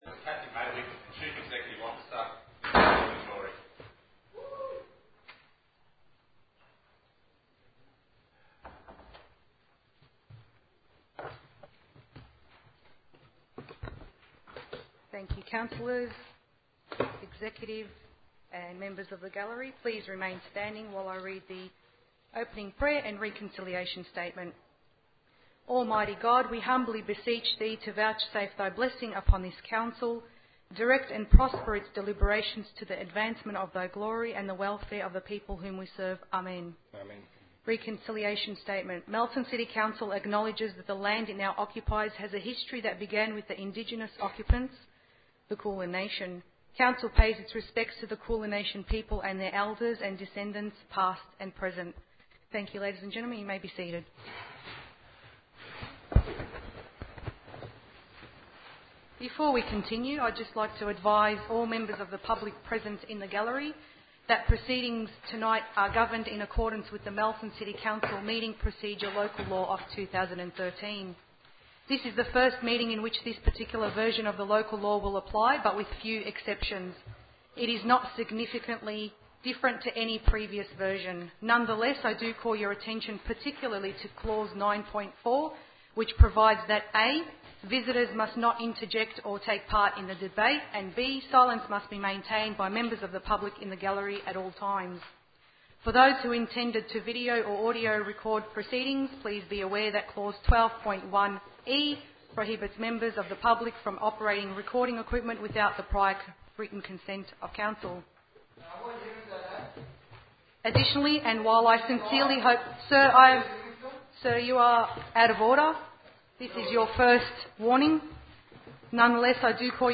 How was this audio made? council_meeting_27-8-13.mp3